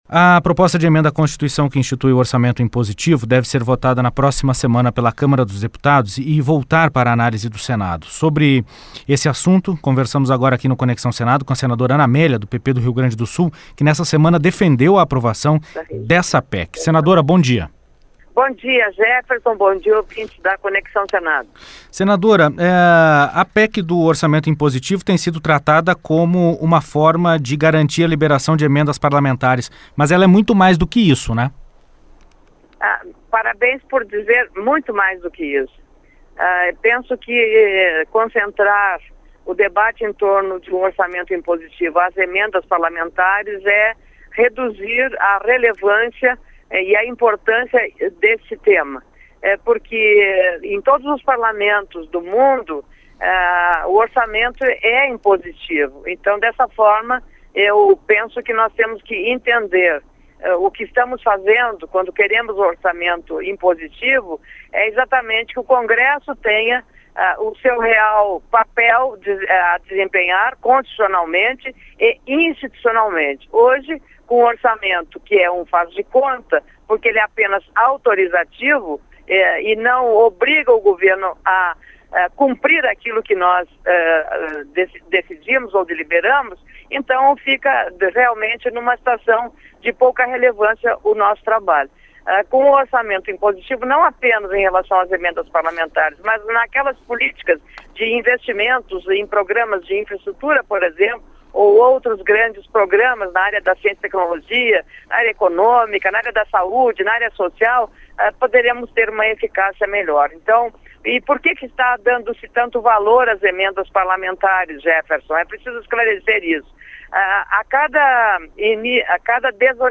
Entrevista com a senadora ¿Ana Amélia (PP-RS).